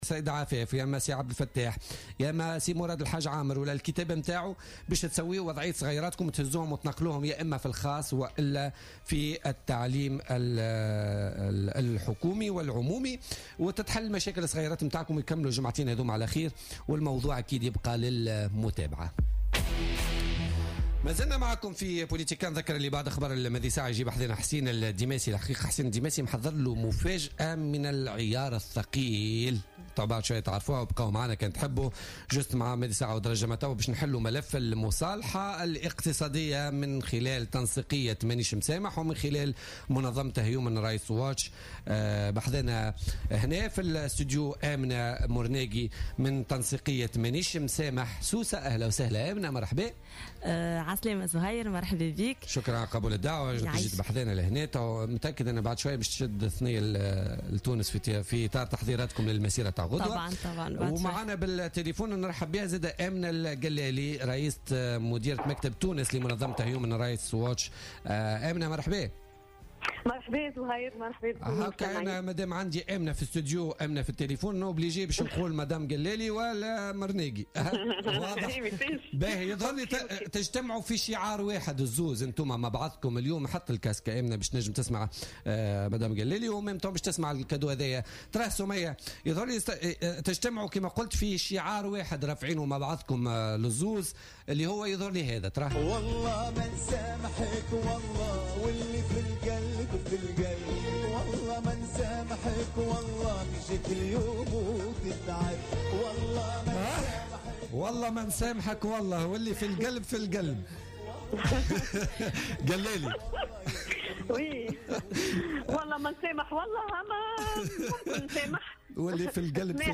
est intervenue vendredi sur Jawhara FM dans le cadre de l'émision Politica
présente au studio de Politica